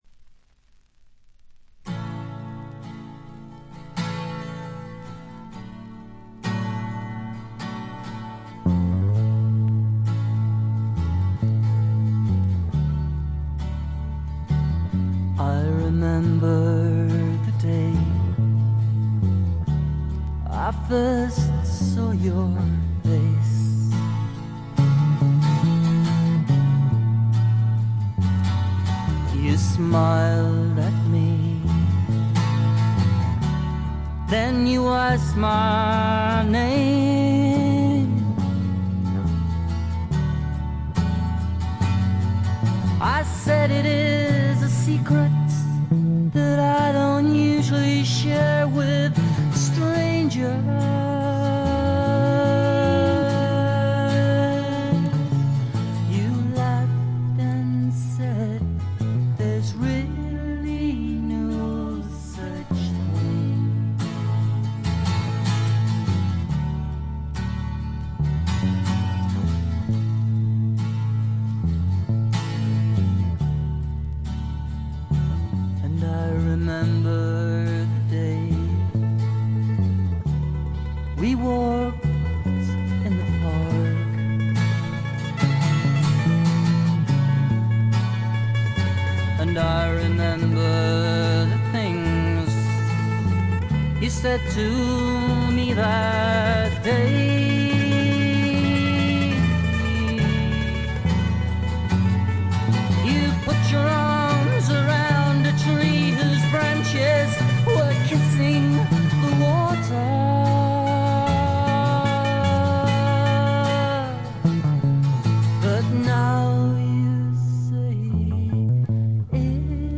軽微なプツ音少々、静音部でわずかなチリプチ。
ギター、マンドリン、ダルシマー等のアコースティック楽器のみによるフォーク作品。
試聴曲は現品からの取り込み音源です。